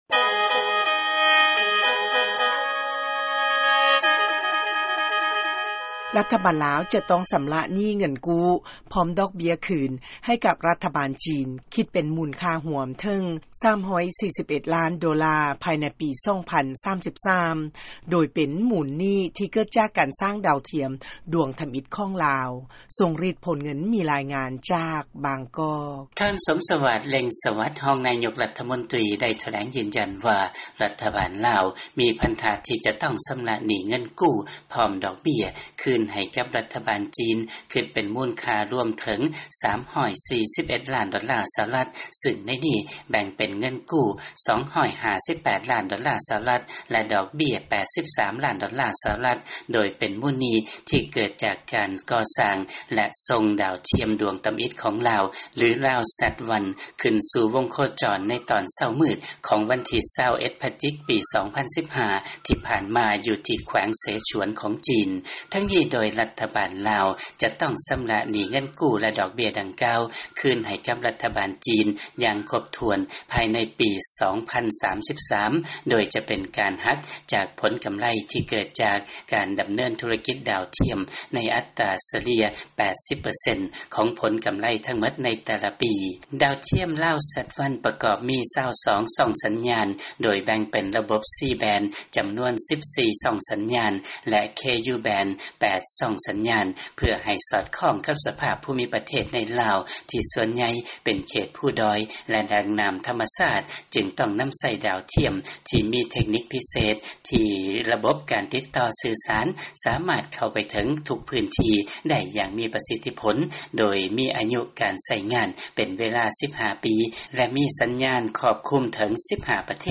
ເຊີນຟັງ ລາຍງານ ລາວຈະຕ້ອງ ຊຳລະໜີ້ເງິນກູ້ ພ້ອມດອກເບ້ຍ ໃຫ້ກັບຈີນ ເຖິງ 341 ລ້ານໂດລາ ພາຍໃນປີ 2033.